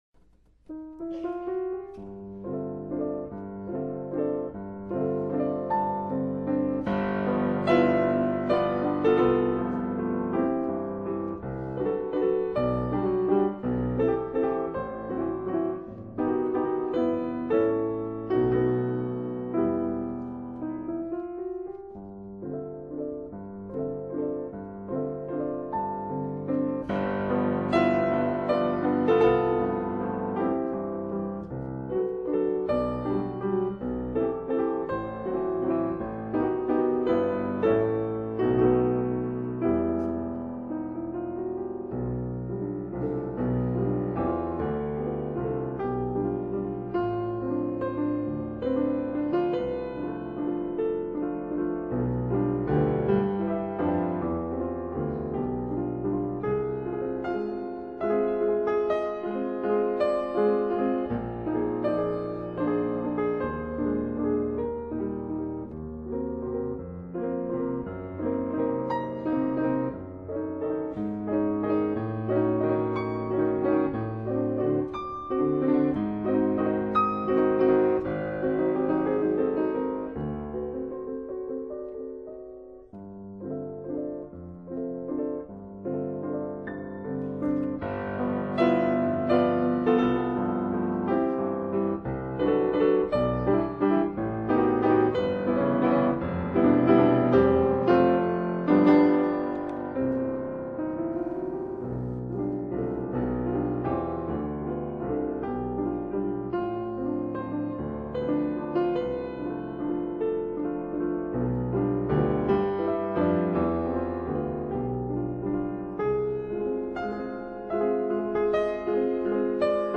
제5곡 '그녀는 춤춘다(Sie tanzt)'은 경쾌하고 즐거운 왈츠로 가벼운 춤동작을 암시해 주는 곡
Sviatoslav Richter, Piano